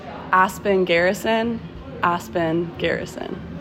Name Pronunciation: